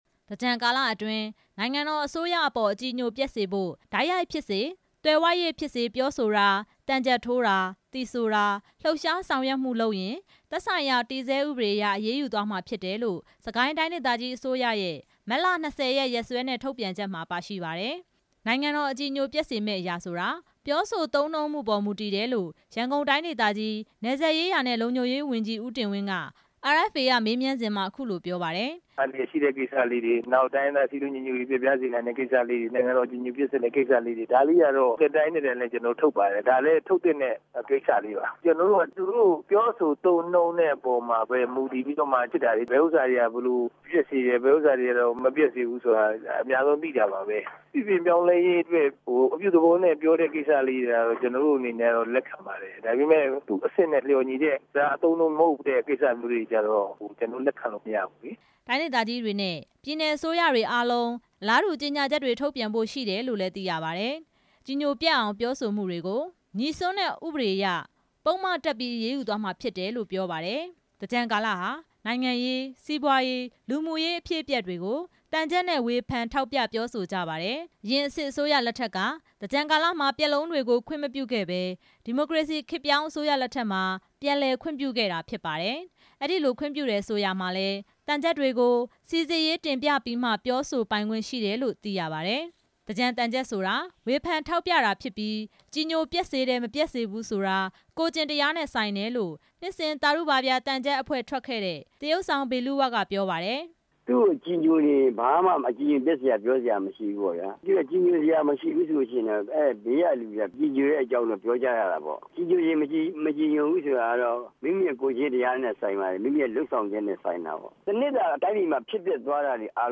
နိုင်ငံတော်အကြည်ညိုပျက်စေမယ့်အရာဆိုတာ ပြောဆိုသုံးနှုန်းမှုပေါ် မူတည်တယ်လို့ ရန်ကုန်တိုင်းဒေသကြီး နယ်စပ်ရေးရာနဲ့လုံခြုံရေးဝန်ကြီး ဦးတင်ဝင်းက အာအက်ဖ်အေက မေးမြန်းစဉ်မှာ အခုလို ပြောပါတယ်။
စစ်ကိုင်းတိုင်းဒေသကြီး အစိုးရအဖွဲ့က ထုြတ်ပန်တဲ့ ကြေညာချက်ဟာ မူးယစ်ရမ်းကားတာနဲ့ ယဉ်မတော်တဆ ဖြစ်မှုတွေကိုကာကွယ်ဖို့ အဓိကဖြစ်တယ်လို့ မုံရွာမြို့နယ် အုပ်ချုပ်ရေးမှူး ဦးညွှန့်စိုးက ပြောပါတယ်။